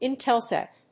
音節In・tel・sat 発音記号・読み方
/íntels`æt(米国英語), ˌɪˈntelsæt(英国英語)/